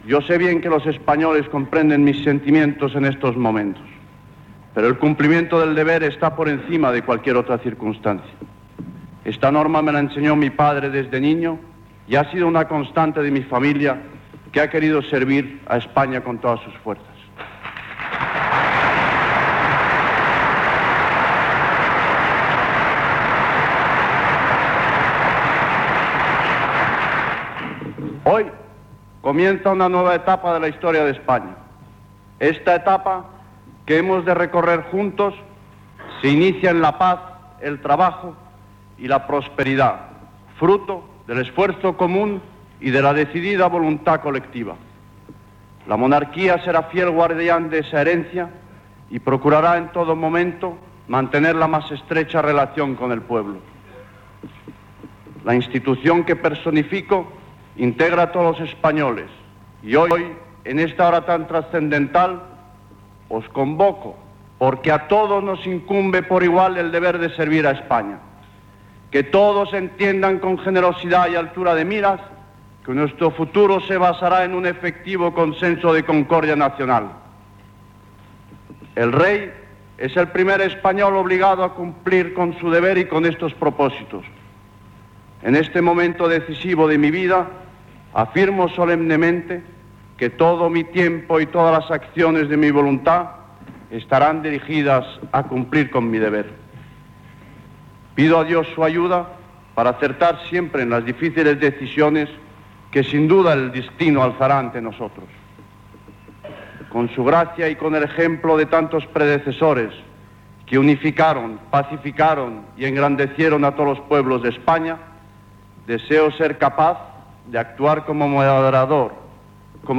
ff09e507671d8a25f4fd8ea335771d749ca3a479.mp3 Títol Radio Nacional de España Emissora Radio Nacional de España Barcelona Cadena RNE Titularitat Pública estatal Descripció Discurs del rei Juan Carlos I a les Cortes, on va ser proclamat cap de l'Estat. Narració del final de l'acte.